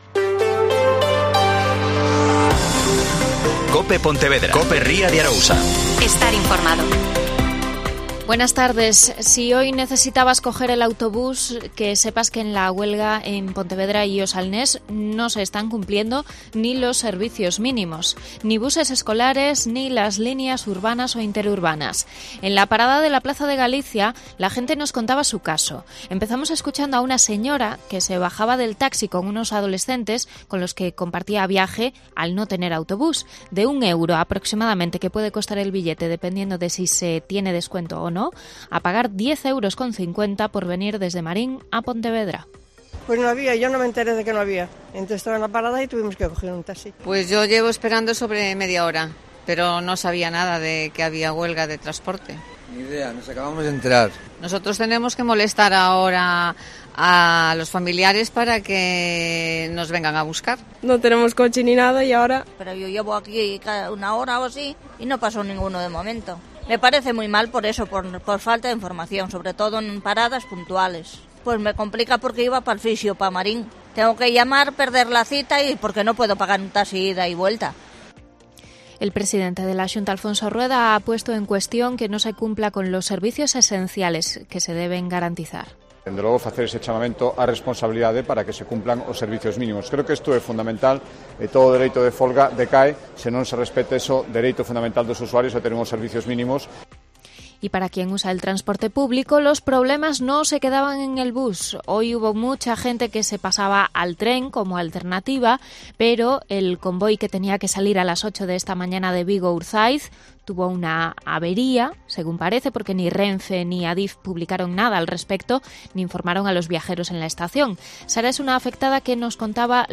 Mediodía COPE Pontevedra y COPE Ría de Arousa (Informativo 14:20h)